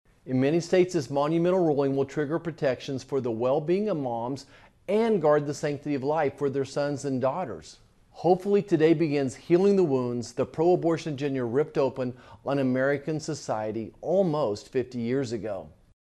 U.S. Senator Roger Marshall reacted to the news Friday morning in a video statement, where he called on Americans to remain peaceful and respectful in the protest of the decision.